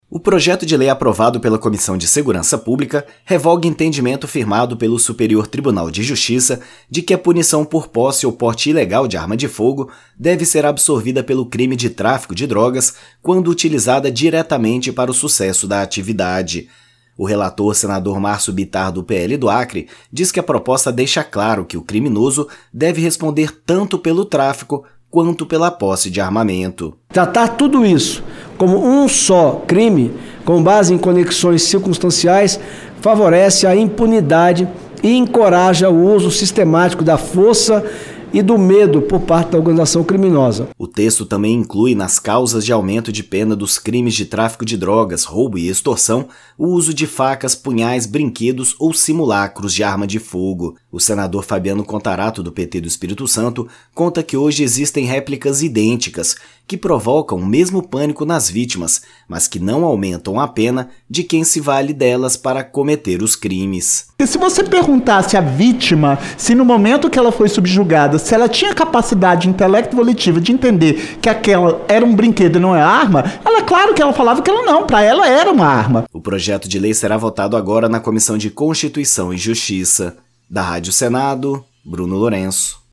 Senador Fabiano Contarato
Senador Márcio Bittar